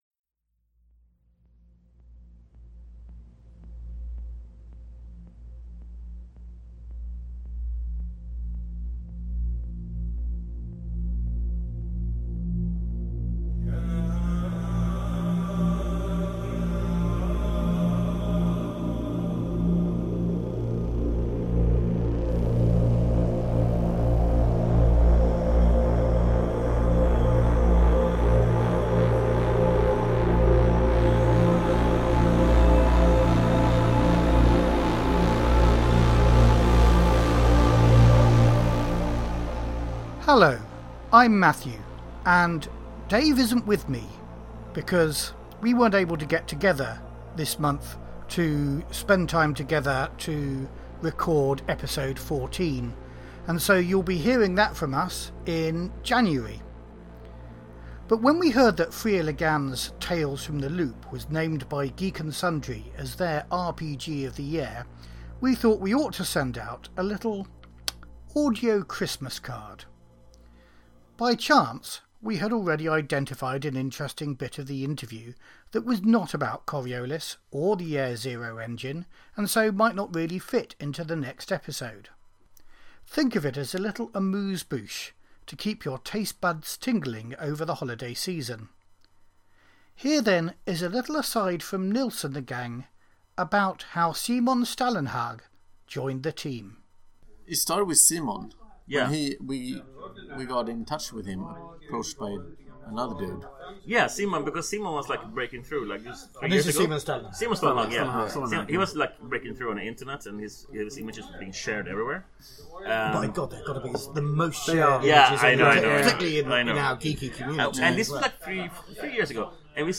With music by Stars on a Black Sea, used with permission of Free League Publishing.